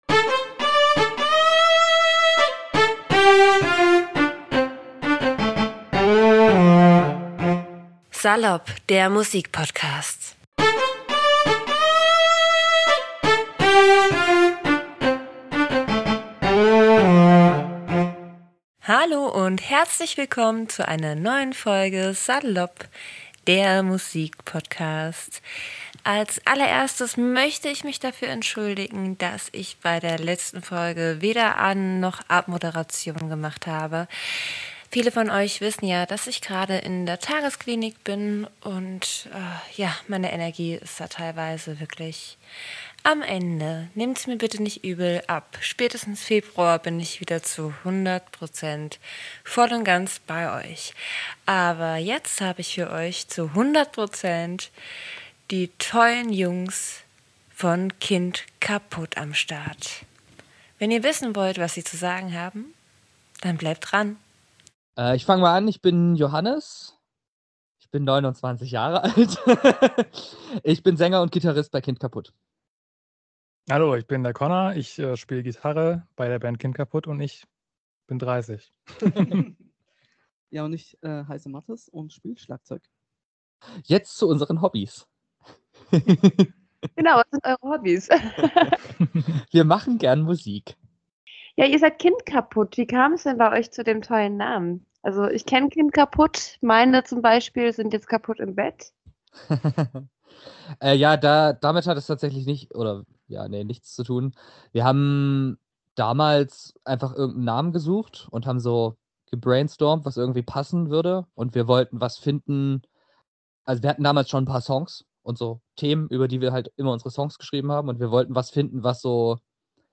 In dieser Folge habe ich ein 3/4 der sympathischen Band Kind Kaputt in meinem virtuellen Salopp-Wohnzimmer. Die Band, die offiziell als Post-Hardcore-Band gilt, ist aber viel mehr als das und drückt sich aus allen Genre-Schubladen!